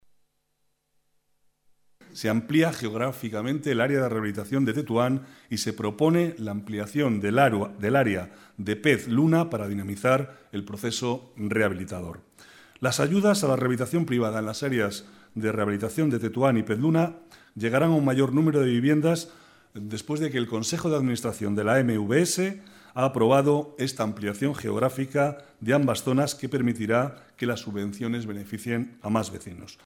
Nueva ventana:Declaraciones del vicealcalde, Manuel Cobo: Más viviendas rehabilitadas en Tetuán y Pez-Luna